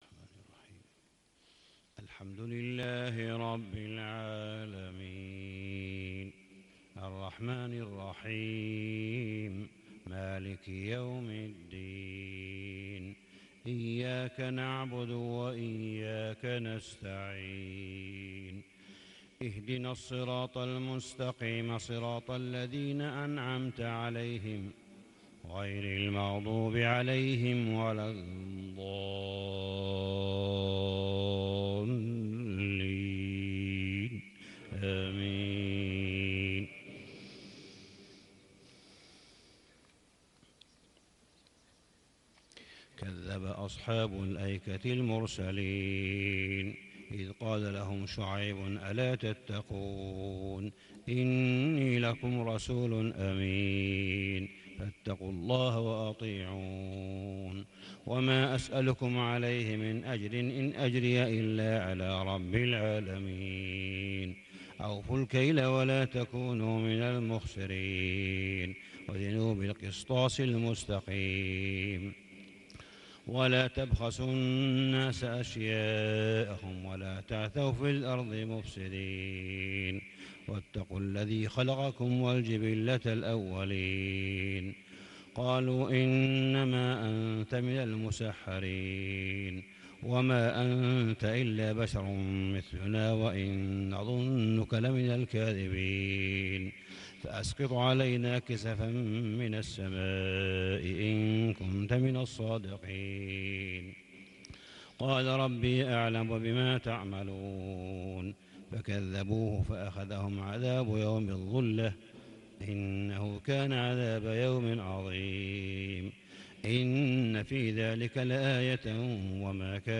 صلاة الفجر 6-7-1438هـ من سورة الشعراء | Fajr 3-4-2017 prayer from Surah ash-Shu`ara > 1438 🕋 > الفروض - تلاوات الحرمين